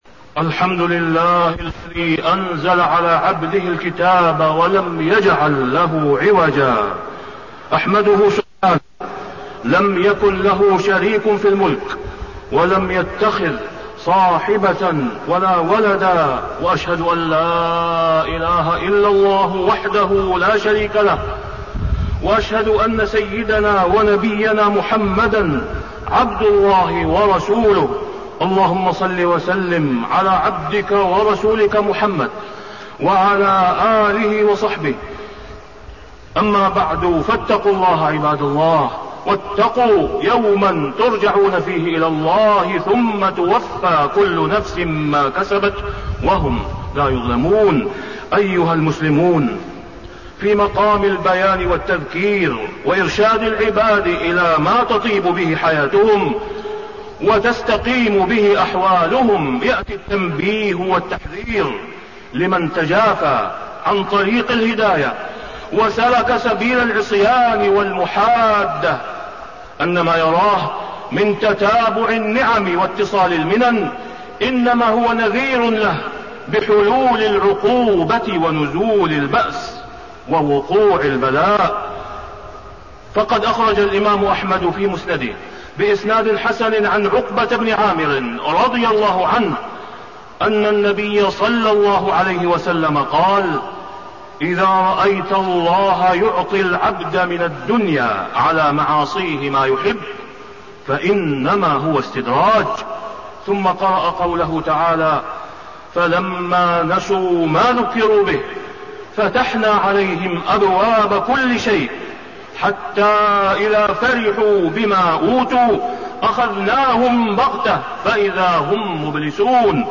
تاريخ النشر ٣٠ جمادى الأولى ١٤٣١ هـ المكان: المسجد الحرام الشيخ: فضيلة الشيخ د. أسامة بن عبدالله خياط فضيلة الشيخ د. أسامة بن عبدالله خياط خطورة الاستدراج